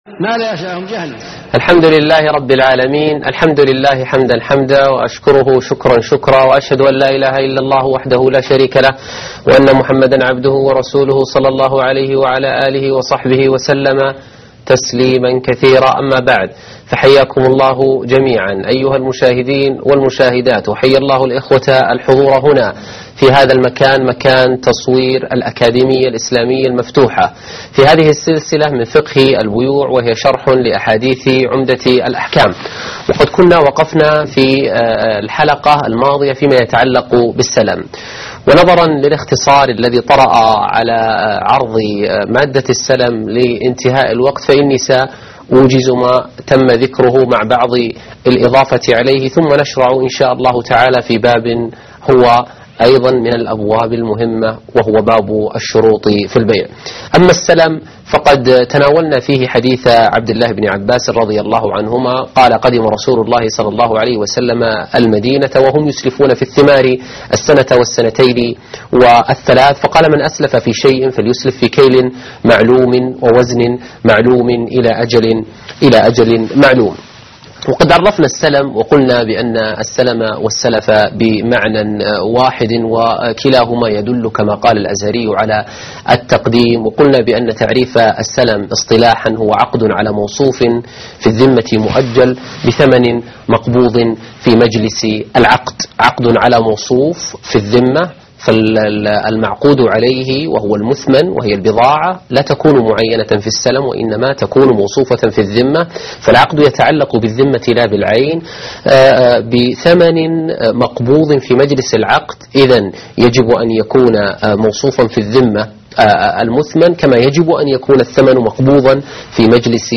الدرس 11: السَّلَم _ الشروط في البيع